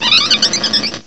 cry_not_helioptile.aif